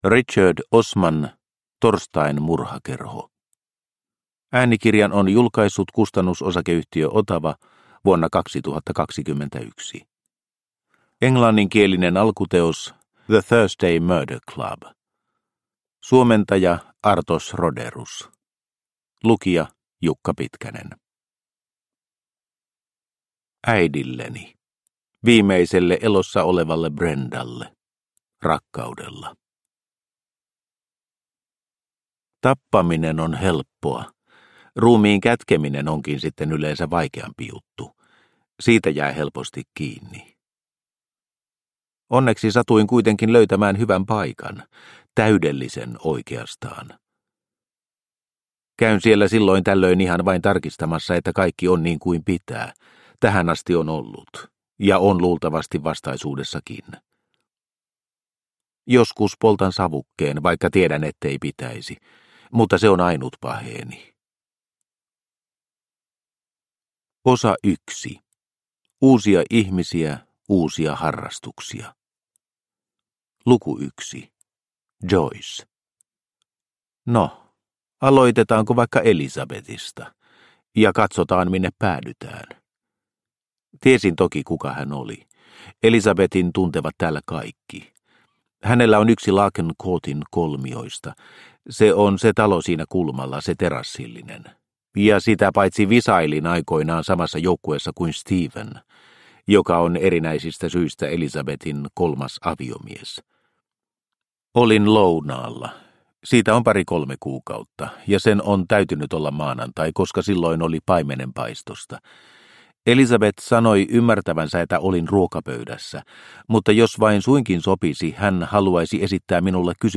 Torstain murhakerho – Ljudbok – Laddas ner